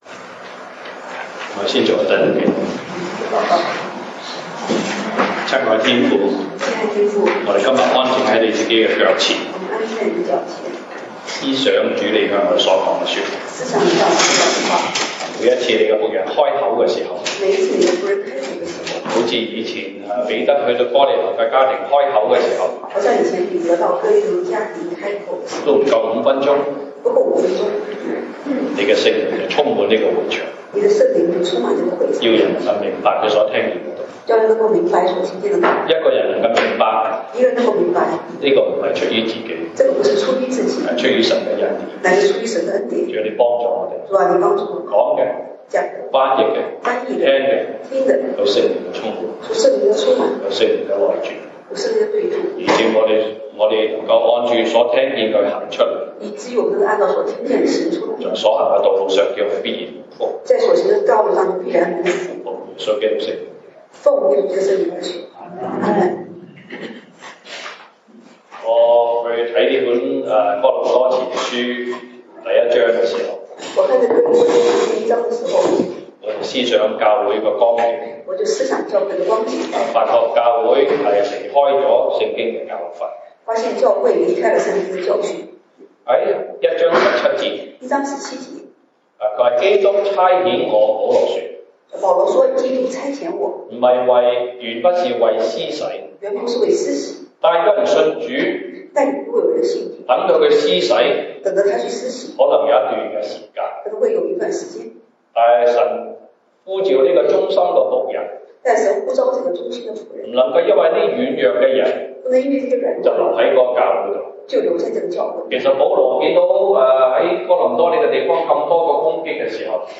西堂證道(粵語/國語) Sunday Service Chinese: 神奧秘莫測的道理